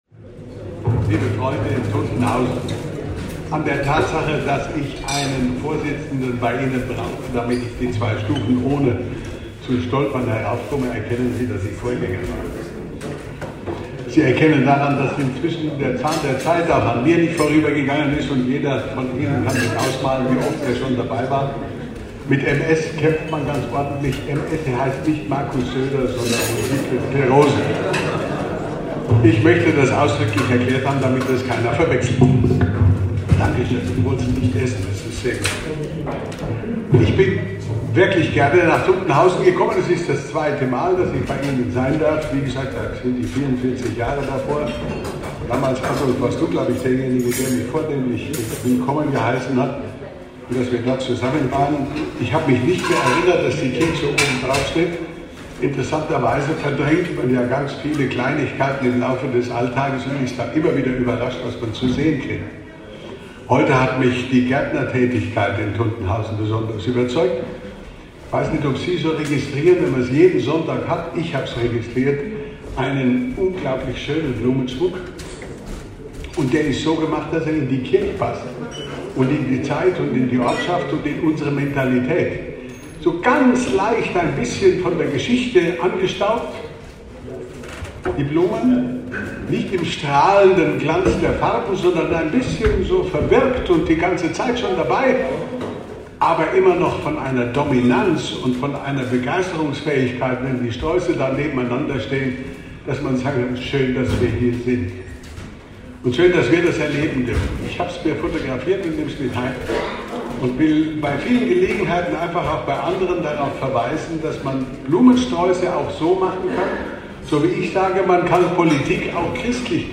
Ansprache von Dr. Thomas Goppel bei der Herbstwallfahrt 2021 | Katholischer Männerverein Tuntenhausen